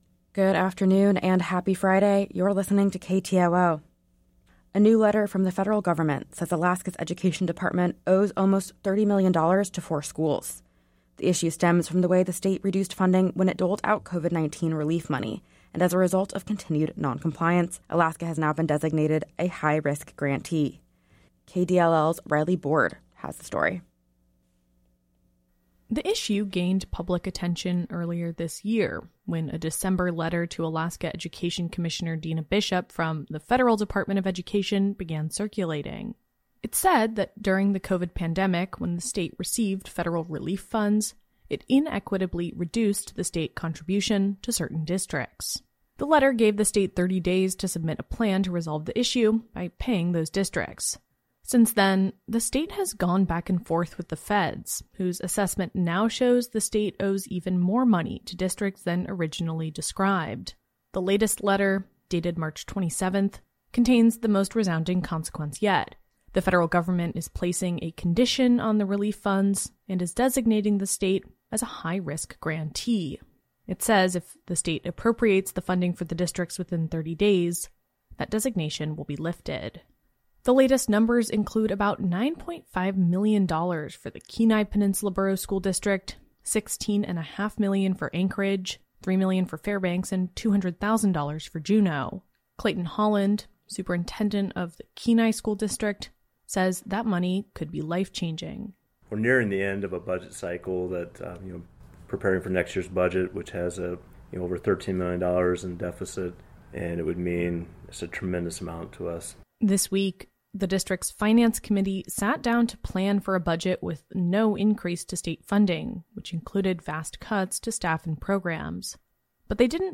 Newscast – Friday, March 29, 2024